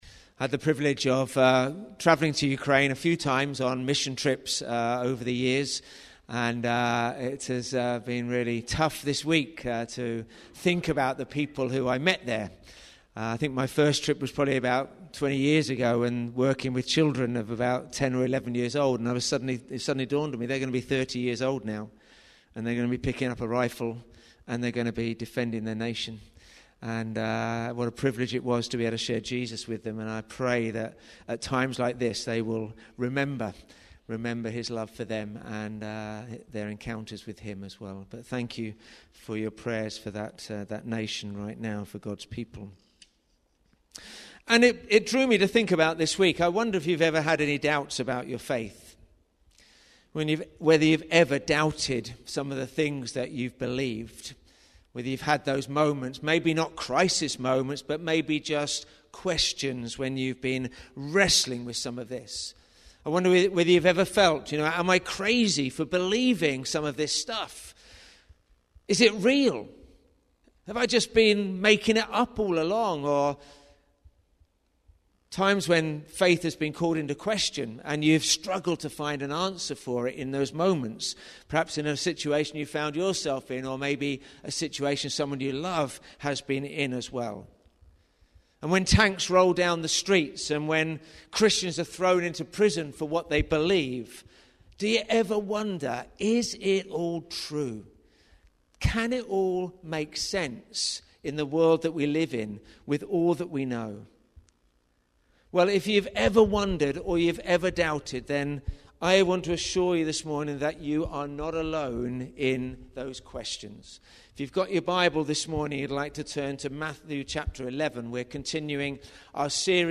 The Gospel of Matthew Watch Listen play pause mute unmute Download MP3 Thanks for joining us as we join together in worship both in-person and online. We're continuing our journey through Matthew's gospel this morning as we explore Jesus' teaching in Matthew 11.